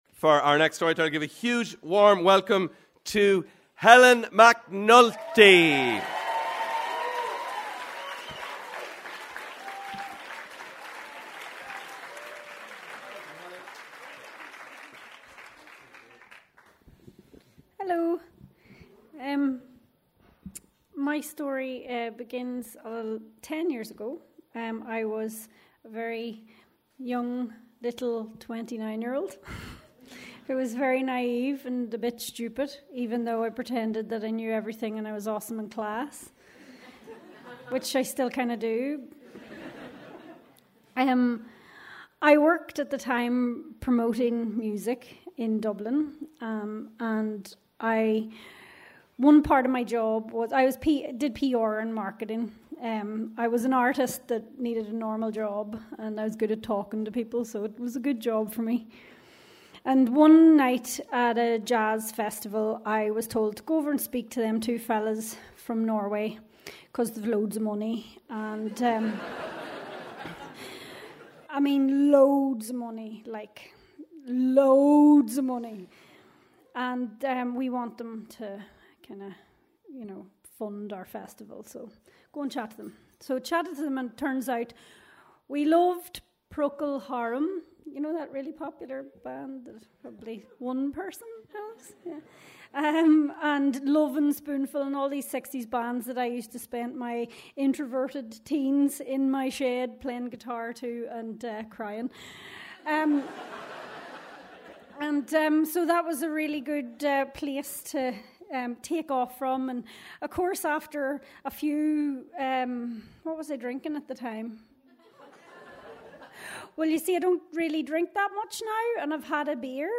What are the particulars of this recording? Recorded at Dublin Story Slam in August 2019